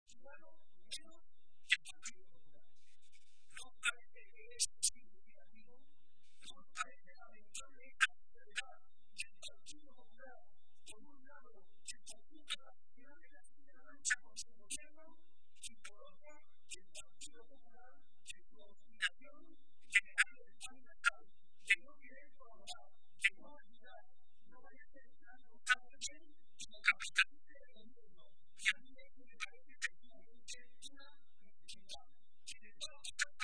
Barreda realizó estas manifestaciones durante la celebración de la tradicional comida navideña del PSOE de Toledo, que ha tenido lugar hoy en la capital regional, y a la que también asistió la ministra de Sanidad y Política Social, Trinidad Jiménez.